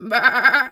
sheep_2_baa_05.wav